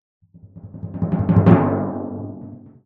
QuestAddedSound.ogg